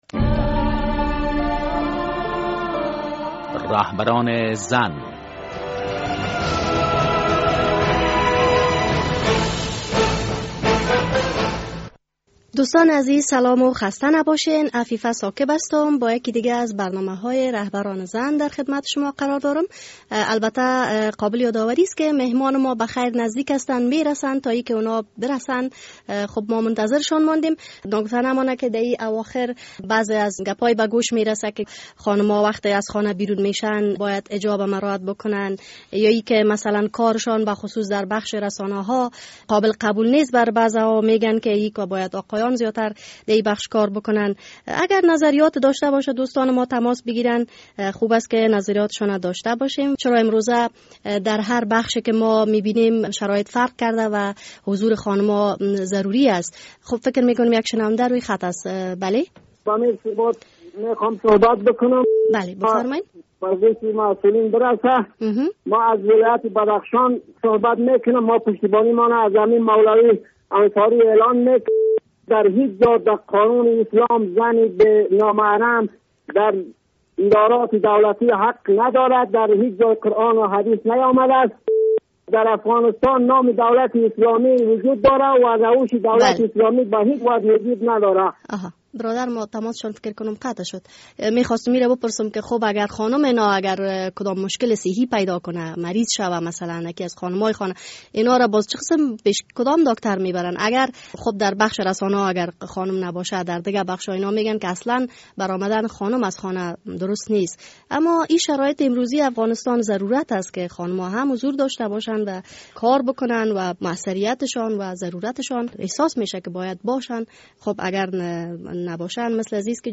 در این هفته داکتر خدیجه الهام وکیل شورای ملی از ولایت کاپیسا مهمان ما هستند.